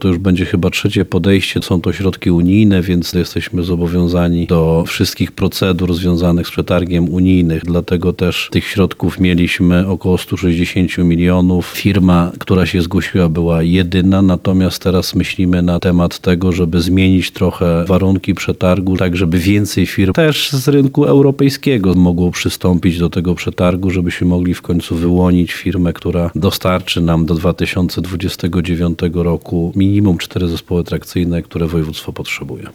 Jak tłumaczy wicemarszałek województwa lubelskiego Piotr Breś, wygrana firma została poproszona o uzupełnienie dokumentacji, która była nieaktualna.